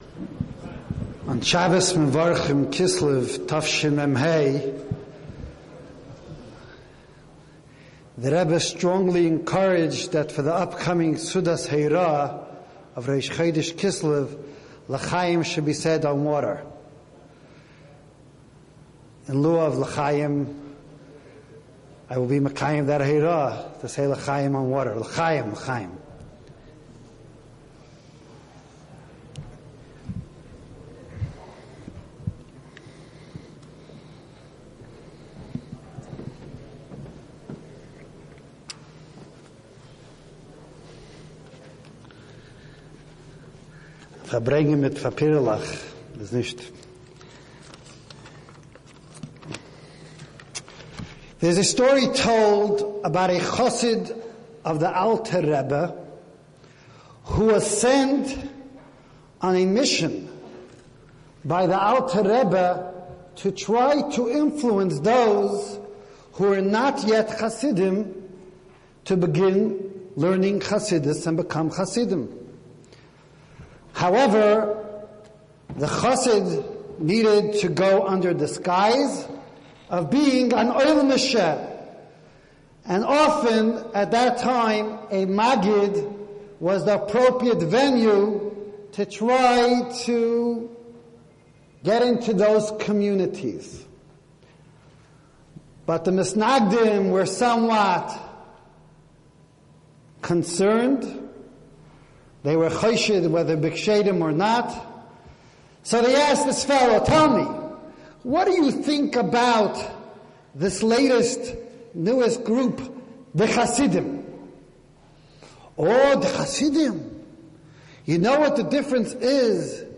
לרצות ולבקש משיח באמת ● משא מאלף והרצאה להאזנה